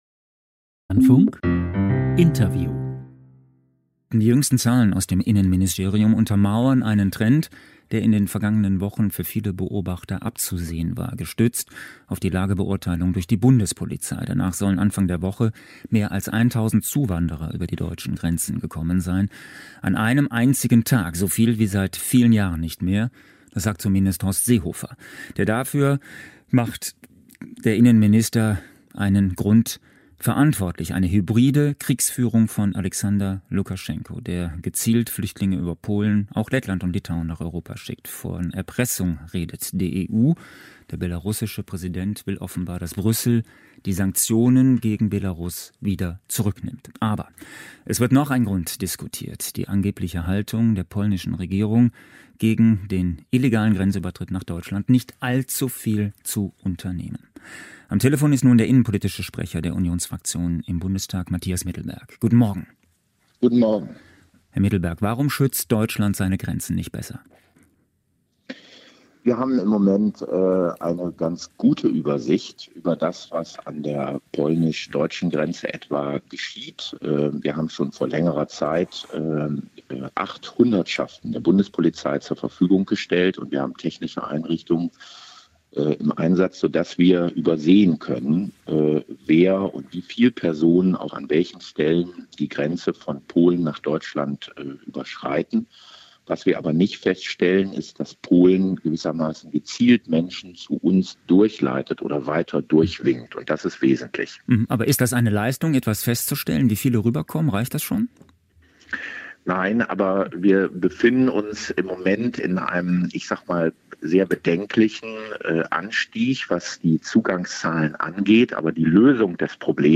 Das Interview des Dlf mit Mathias Middelberg (CDU) am 29.10.2021